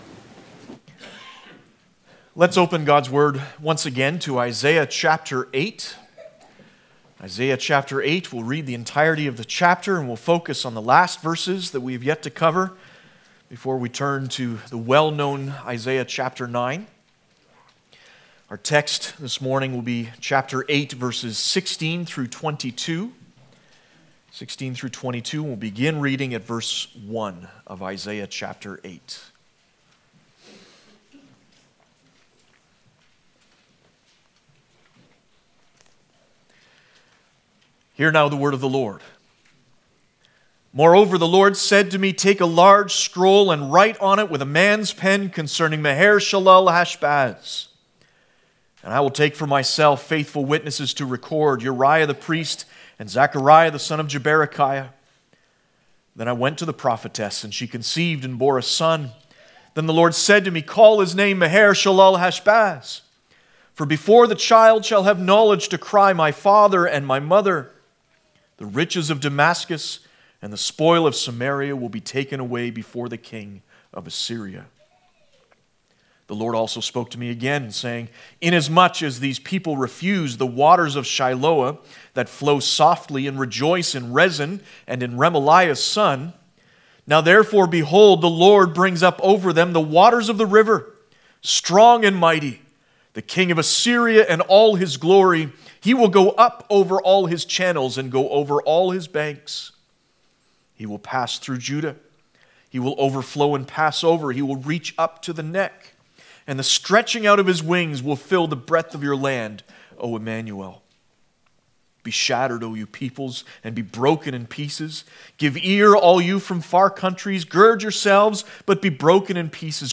Passage: Isaiah 8 Service Type: Sunday Morning